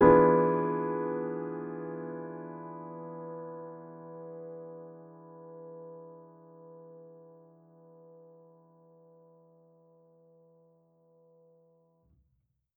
Index of /musicradar/jazz-keys-samples/Chord Hits/Acoustic Piano 1
JK_AcPiano1_Chord-E7b9.wav